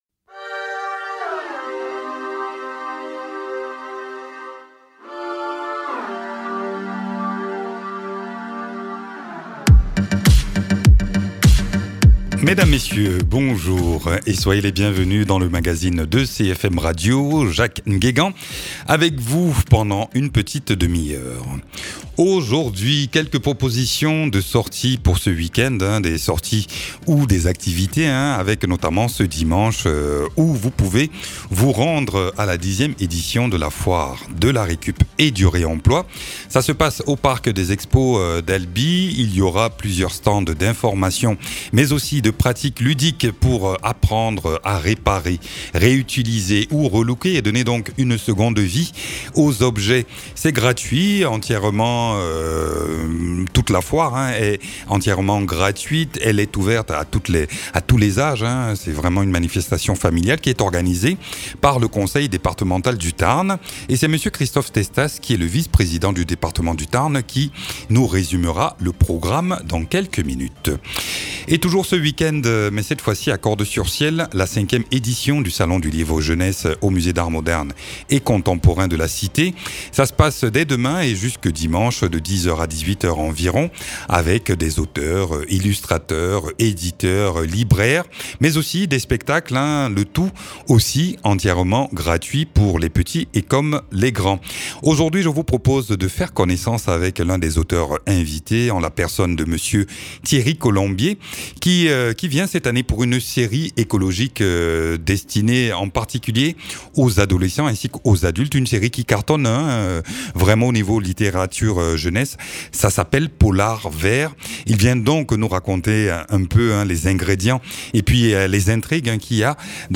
Christophe Testas, Vice-Président du Conseil Départemental du Tarn en charge du développement durable.